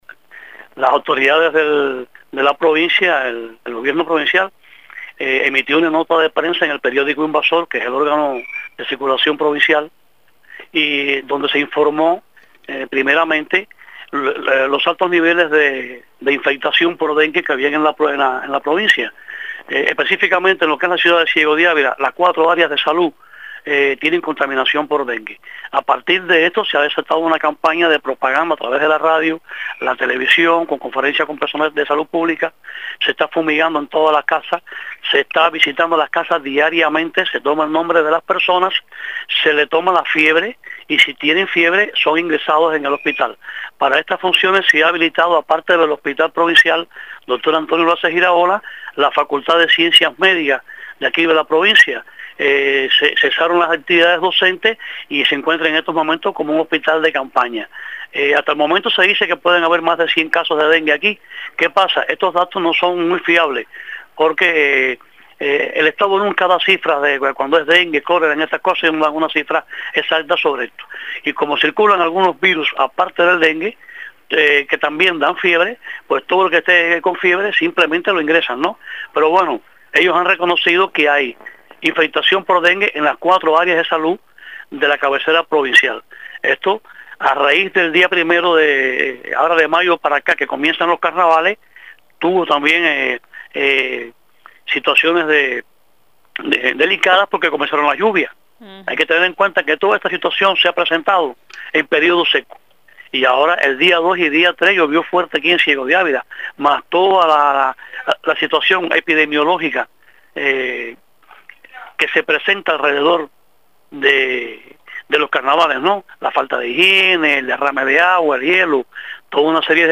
Declaraciones del opositor